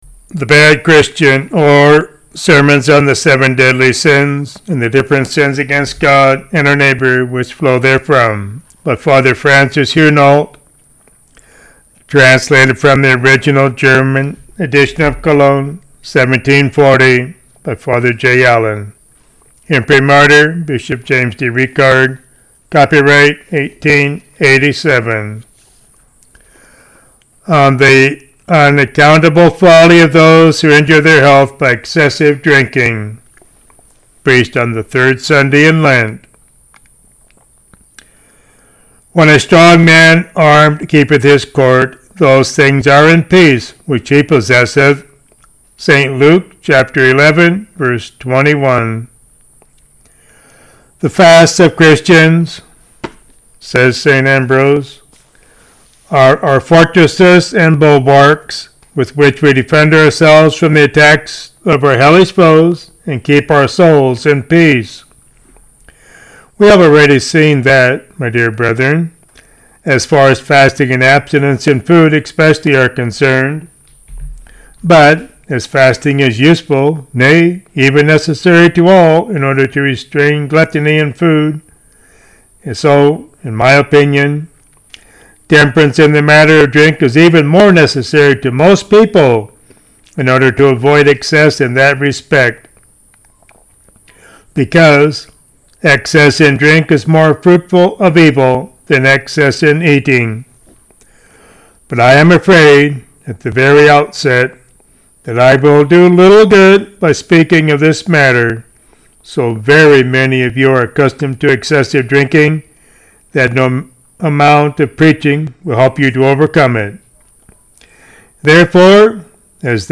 Five Short Sermons